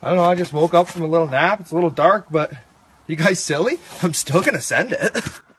ghbot - Discord version of greenhambot -- currently just plays meme sfx in voice channels + static text commands